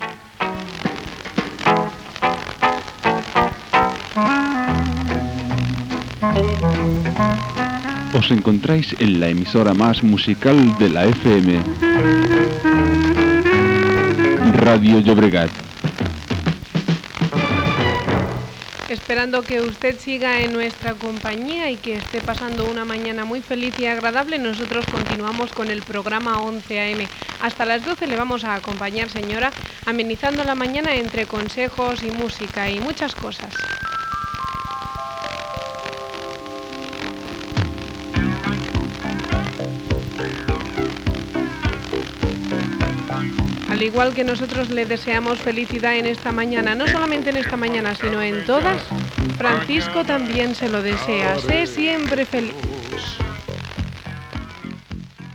Presentació d'un tema musical.
FM